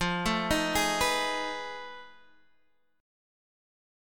F7#9b5 Chord